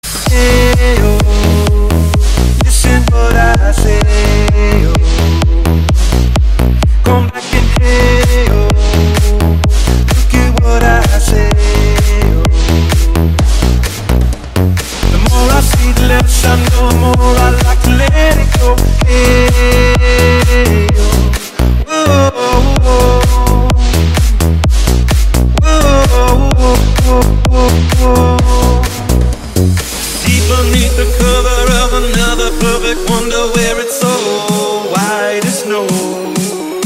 Kategorie Instrumentalny